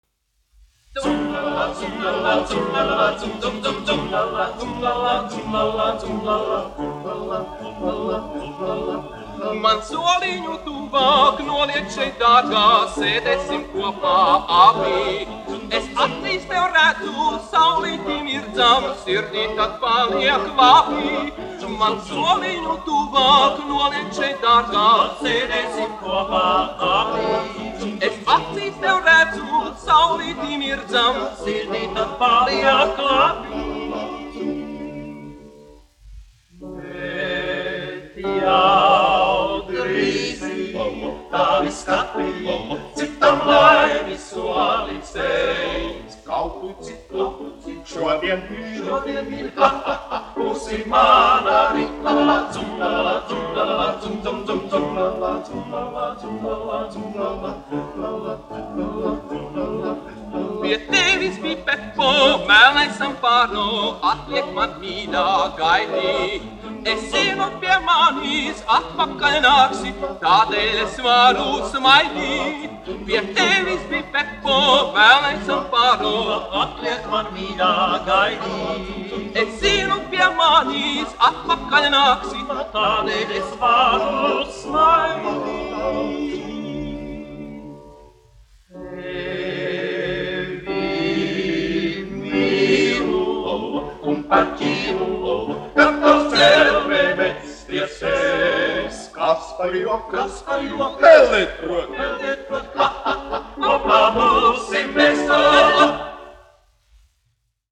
Soliņš : meksikāņu dziesma
Latvijas Filharmonijas vīru vokālais dubultkvartets, izpildītājs
1 skpl. : analogs, 78 apgr/min, mono ; 25 cm
Meksikāņu tautasdziesmas
Vokālie kvarteti ar klavierēm
Latvijas vēsturiskie šellaka skaņuplašu ieraksti (Kolekcija)